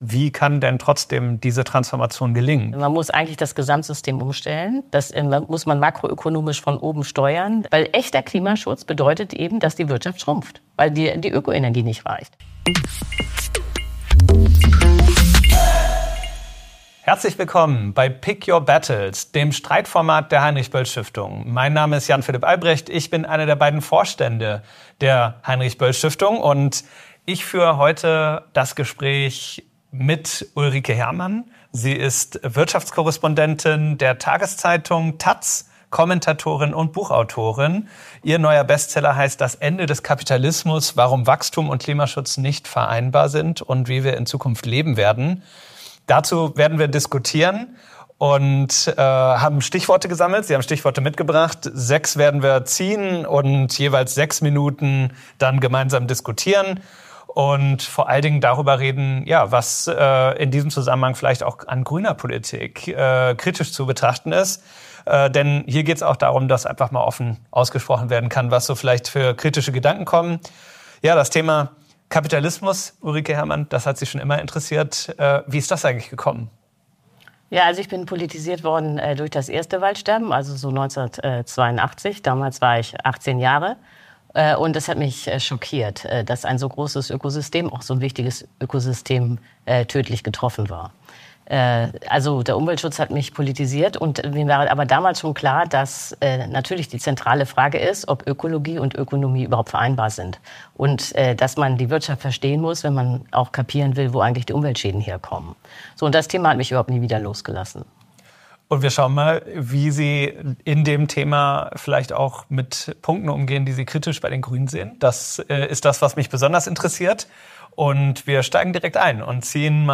Sind die Grünen sozial gerecht? Ist „grünes Wachstum“ ökonomisch überhaupt möglich? Jan Philipp Albrecht, Vorstand Heinrich-Böll-Stiftung, im Gespräch mit Ulrike Herrmann, Journalistin, Autorin, Wirtschaftskorrespondentin taz.